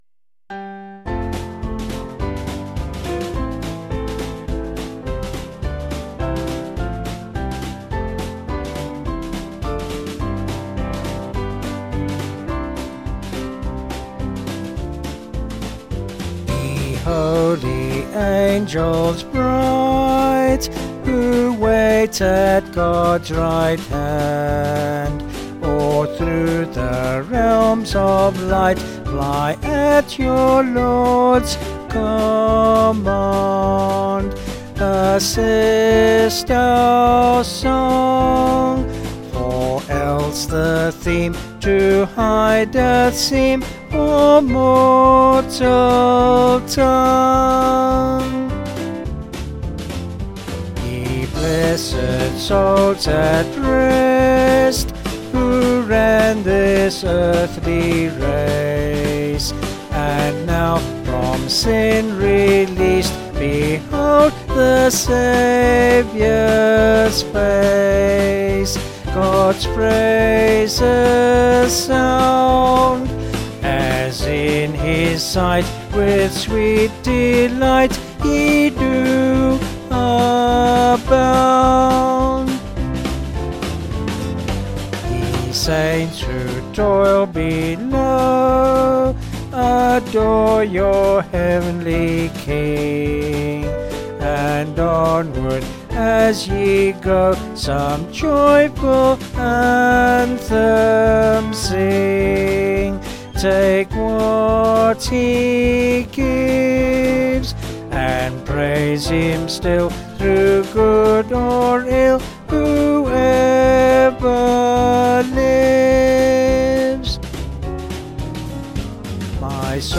Vocals and Band   263.7kb Sung Lyrics